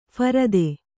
♪ pharade